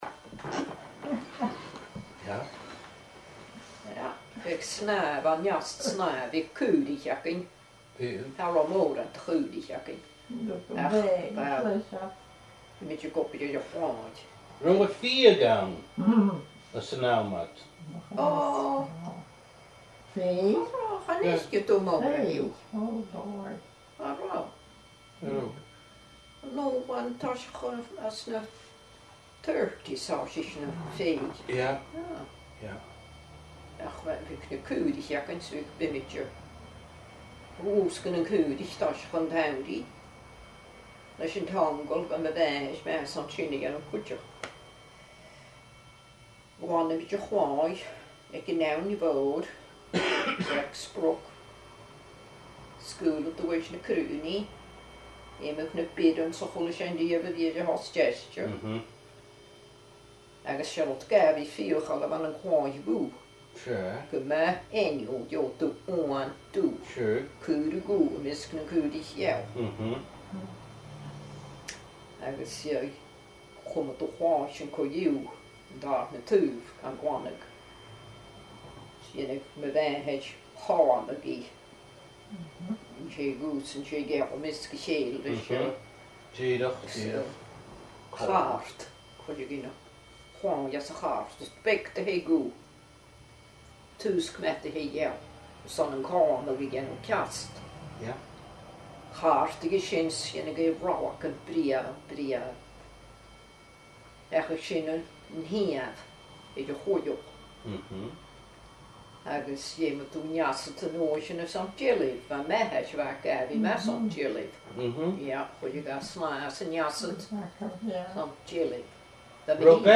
Fear-agallaimh